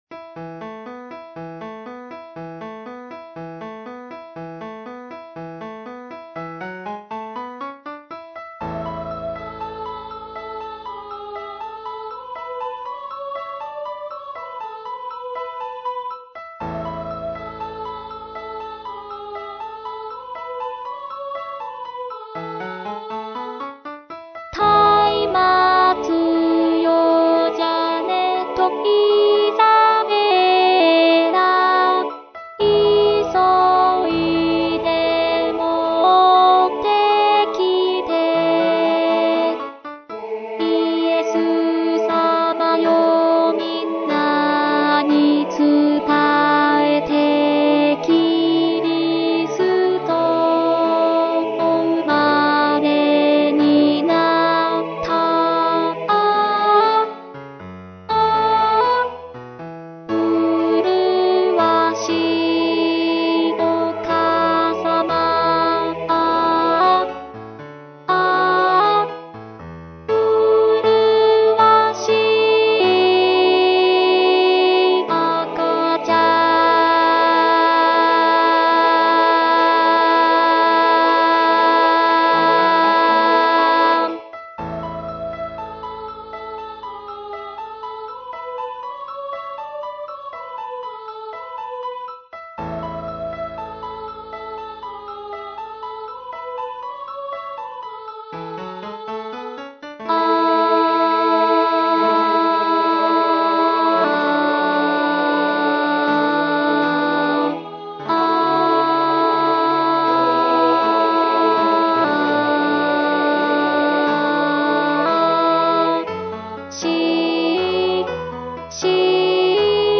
アルト1（歌詞付き）